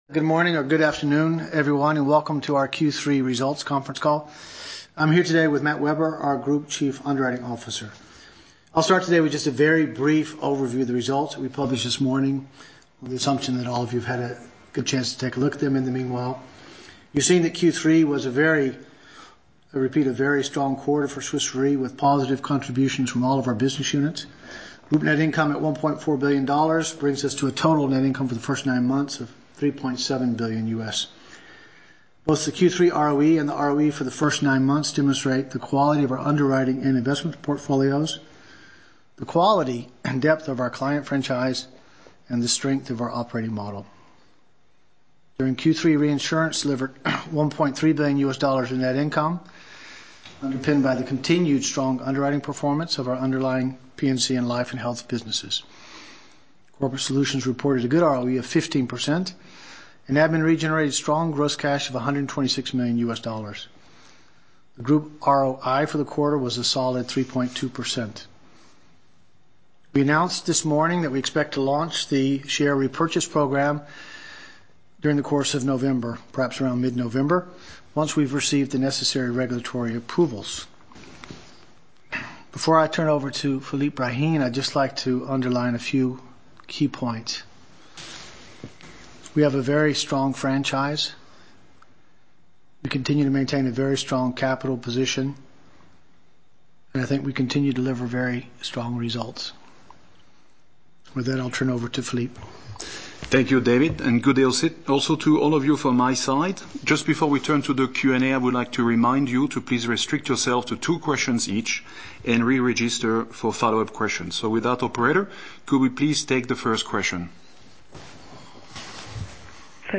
Analysts Conference call recording
2015_q3_qa_audio.mp3